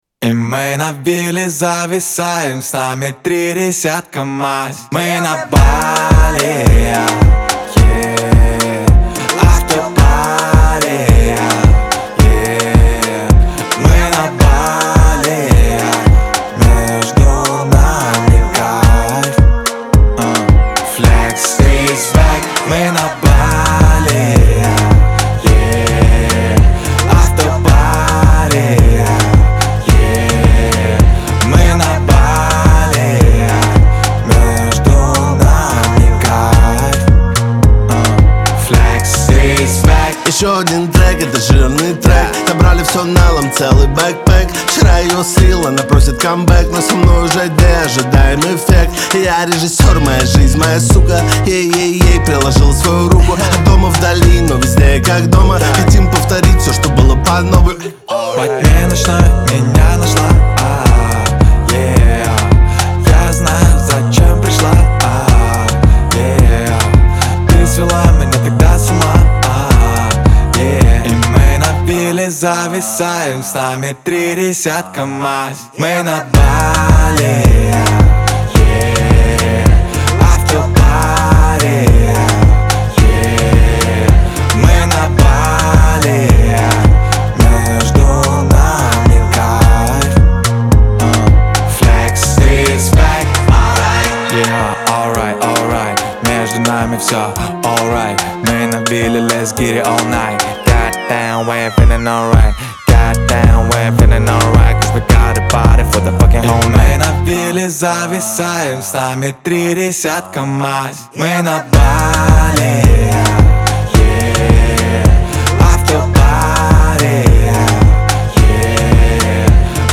ХАУС-РЭП
дуэт
Веселая музыка